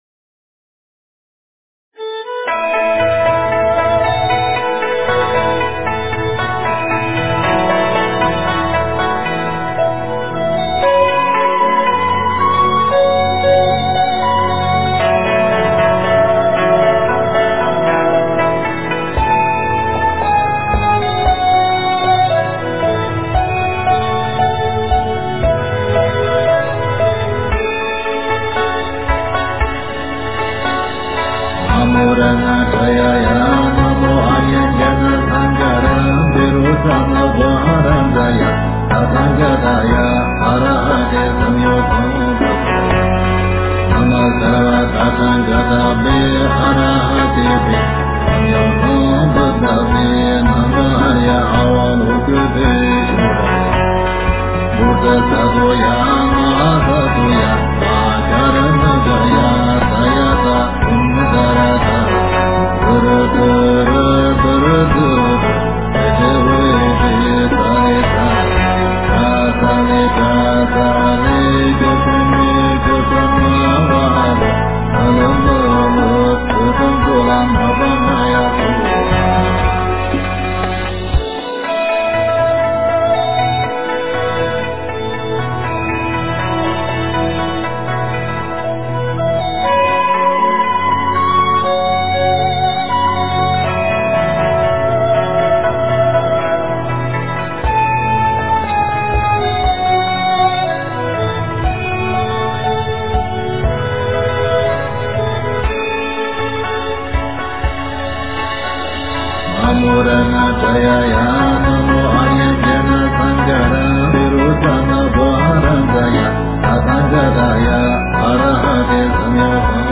诵经
佛音 诵经 佛教音乐 返回列表 上一篇： 大悲咒 下一篇： 心经 相关文章 献供赞（唱诵）--文殊院 献供赞（唱诵）--文殊院...